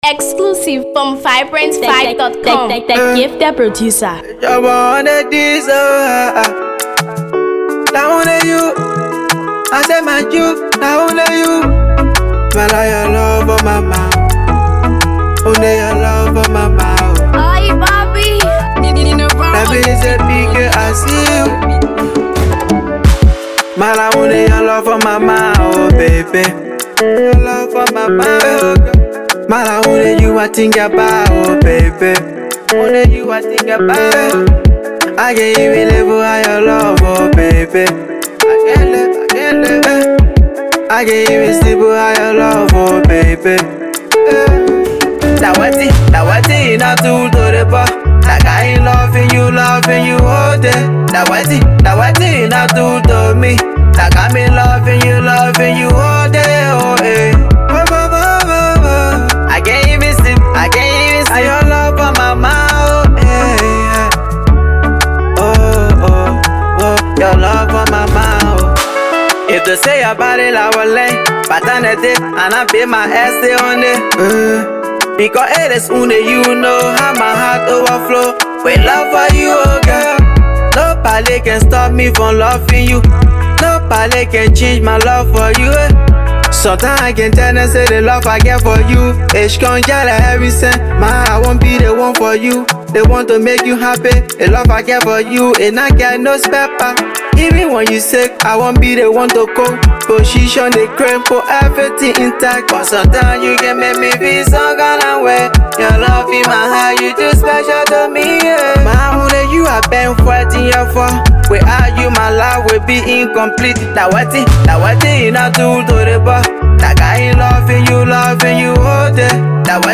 emotional beat